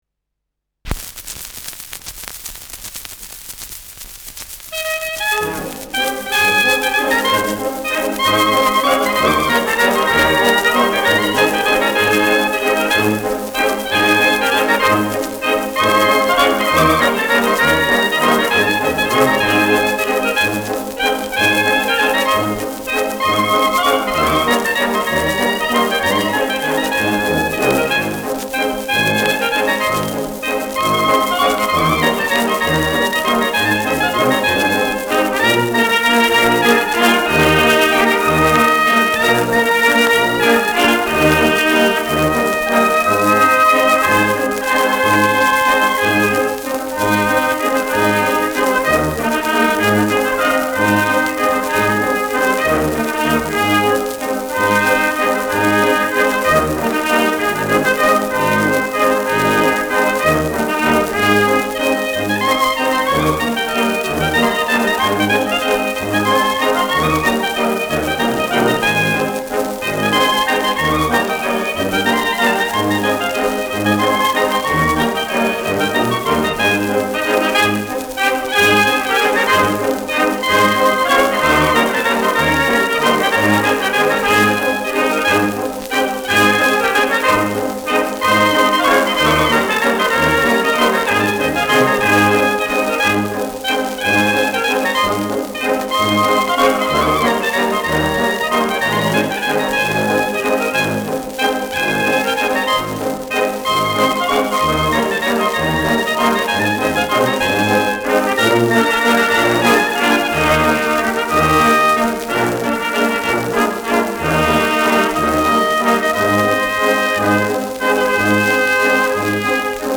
Schellackplatte
präsentes Rauschen